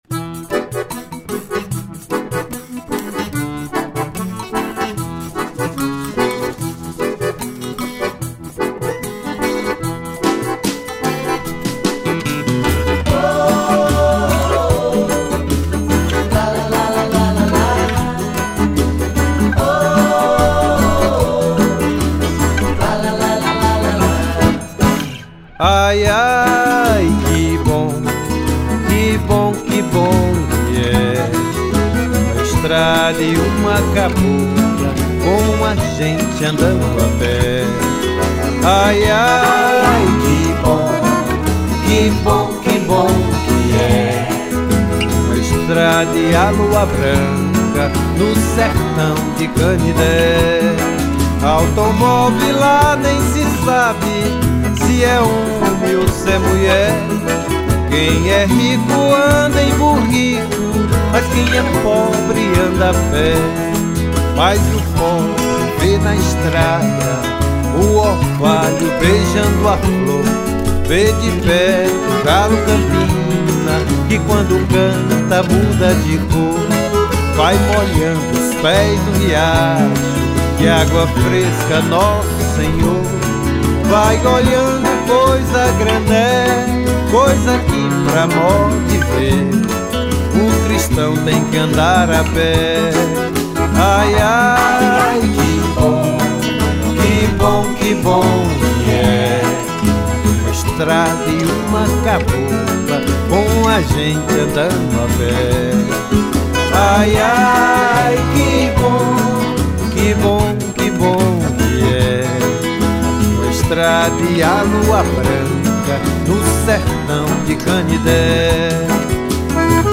1433   03:26:00   Faixa:     Forró
Acoordeon, Voz
Guitarra
Flauta
Percussão